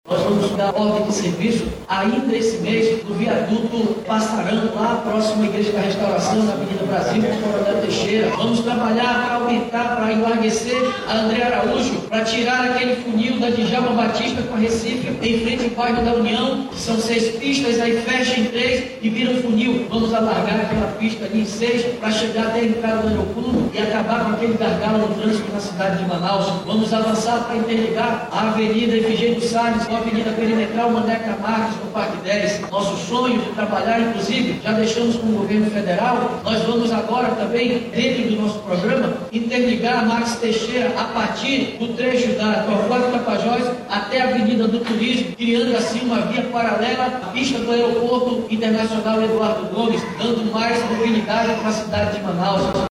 Ainda durante o discurso, no Teatro Amazonas, Davi destacou os investimentos que serão feitos na área de Mobilidade Urbana da cidade, com a execução de várias obras.